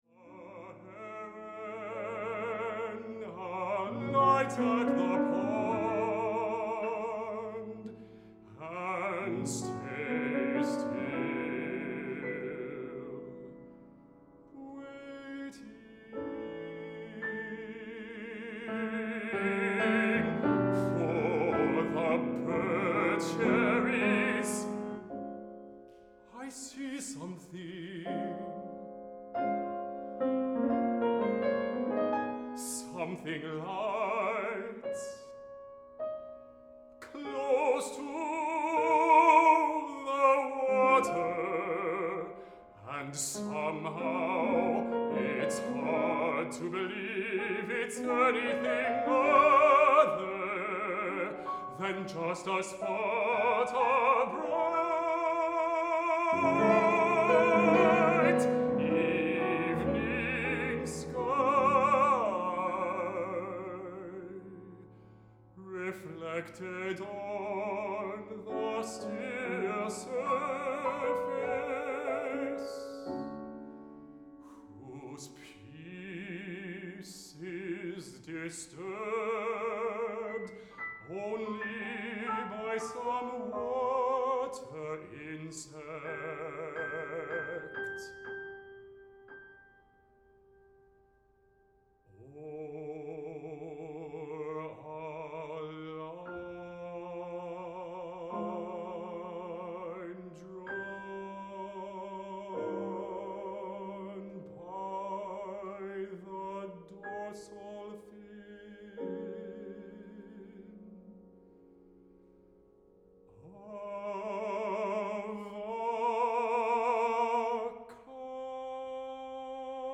baritone
piano
a set of four songs